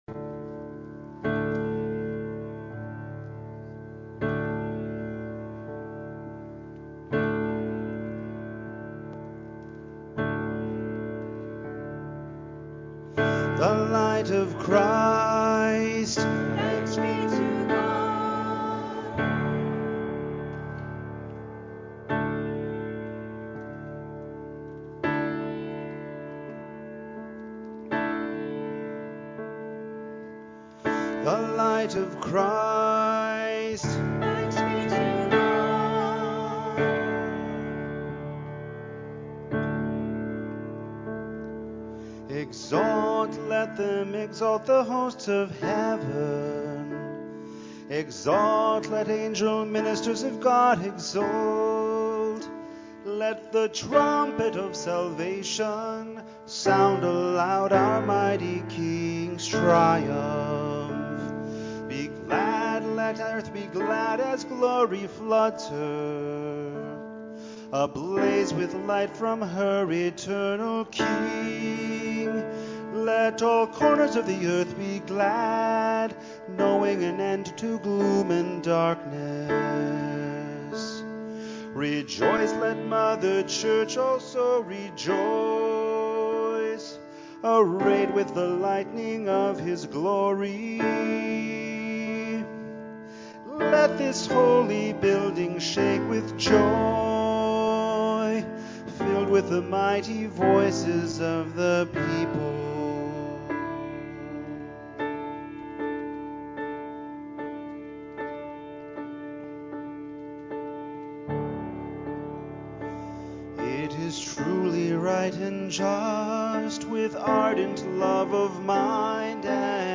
Easter Vigil